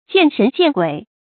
見神見鬼 注音： ㄐㄧㄢˋ ㄕㄣˊ ㄐㄧㄢˋ ㄍㄨㄟˇ 讀音讀法： 意思解釋： 好象看見了鬼神。比喻多疑。